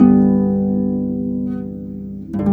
Rock-Pop 09 Harp 04.wav